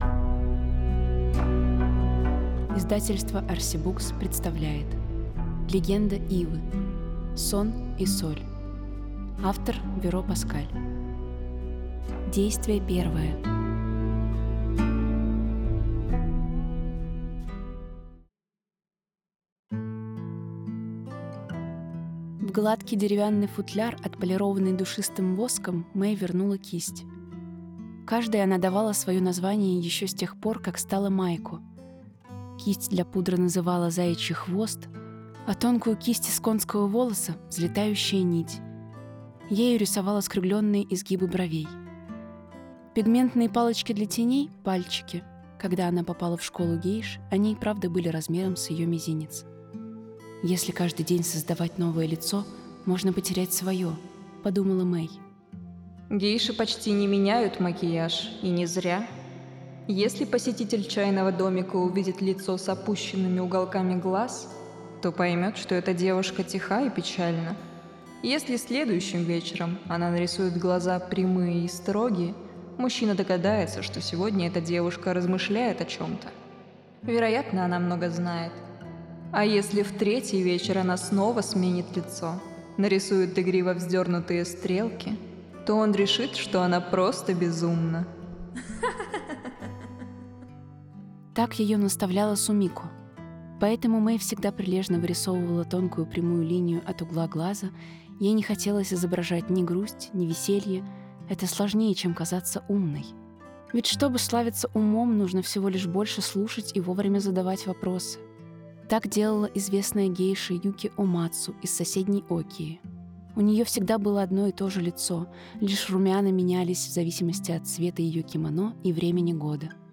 Аудиокнига (аудиоспектакль). Многоголосая версия.